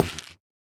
Minecraft Version Minecraft Version 1.21.5 Latest Release | Latest Snapshot 1.21.5 / assets / minecraft / sounds / block / nether_wood_hanging_sign / step2.ogg Compare With Compare With Latest Release | Latest Snapshot
step2.ogg